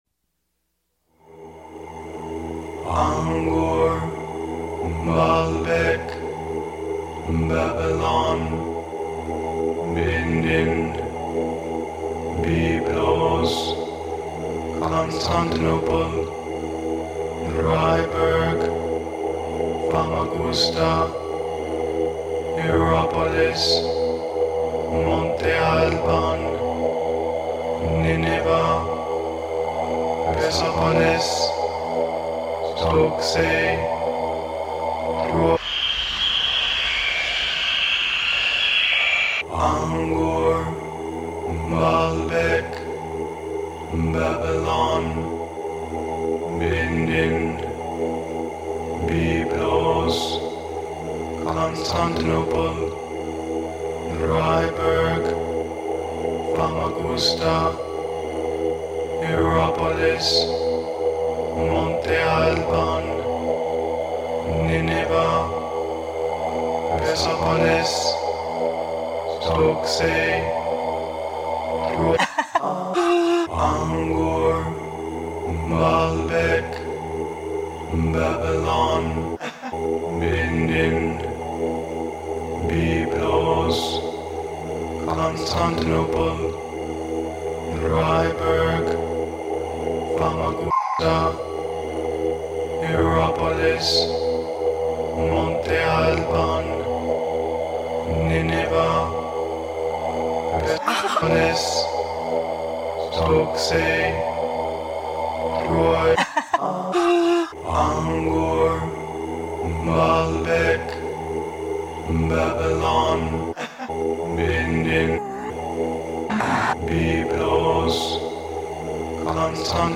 Inspired by the book with the same title (by Rose Macauley, with remarkable photographs by Roloff Beny), I set out to conjure an acoustic ruin through the poetic disintegration of a chanted list of global ruins, using the technique of rhythmic cyclical “eruptions” that I had developed in Disorder Speech.
At the time, there was a good deal of heavy cultural theory about libidinal flows and “economies of pleasure” in the air during the late 1980s, an irresistible invitation for humor; thus I proposed a sort of radiophonic archeology of pleasure, unfolding (or degenerating) in real time.
With the exception of a tour guide speleologist and a few other documentary scraps, the only voice used is my own, through a variety of personae.